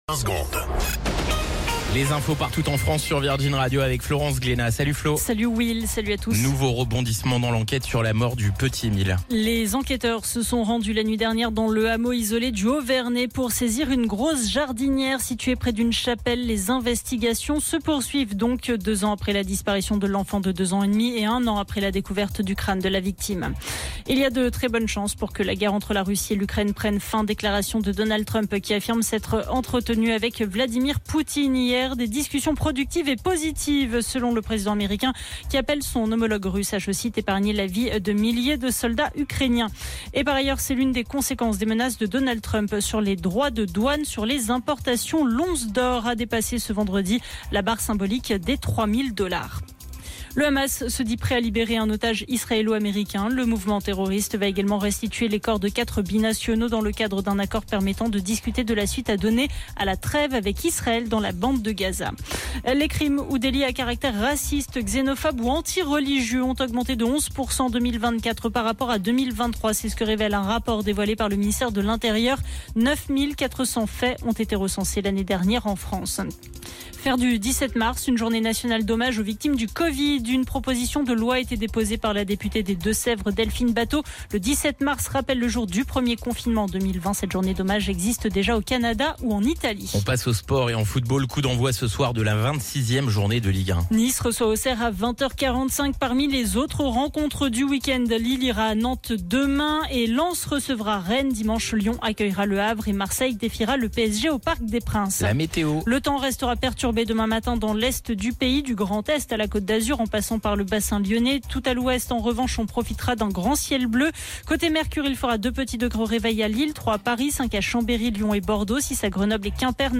Flash Info National 14 Mars 2025 Du 14/03/2025 à 17h10 .